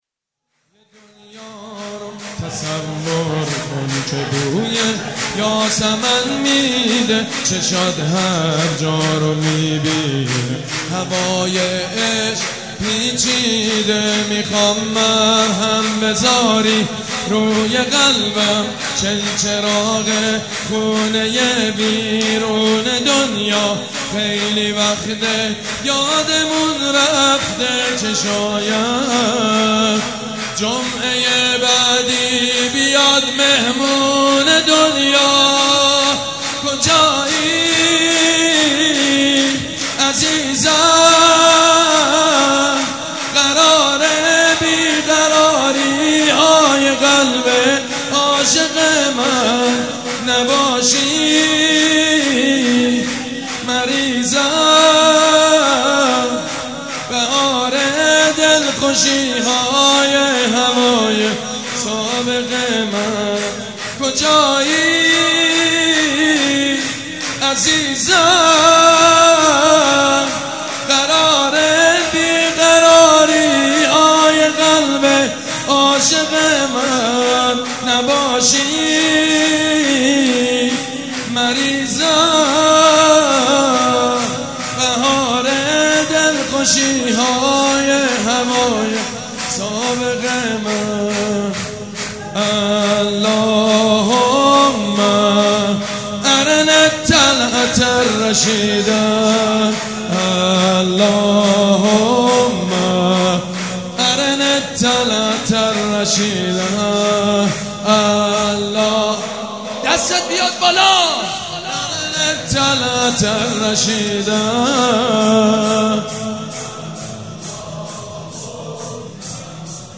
سرود: همه دنیا رو تصور کن که